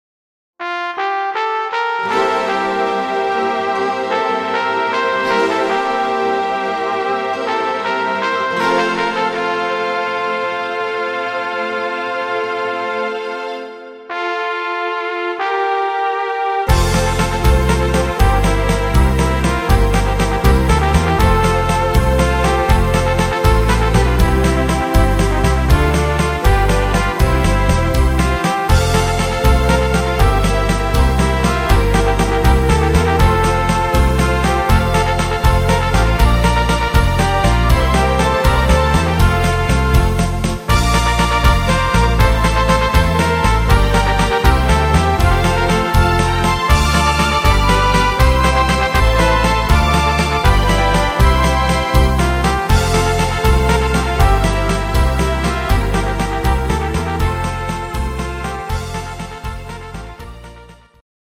instr. Trompeten